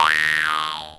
Zany boing sound as a character jumps. 0:12 A comedic bounce followed by a funny 'boing' noise. 0:12 A comedic 'boing' sound that rises in pitch. 0:10 A boing sound made with a Jaws harp. Recorded with a behringer C2 pencil condenser into an m-audio projectmix i/o interface. Ver little processing, just topped and tailed. 0:01 A high-pitched 'boing' sound of a spring being released. 0:12 A comical boing sound as a character jumps into the air. 0:11
a-boing-sound-made-with-jnckgn3g.wav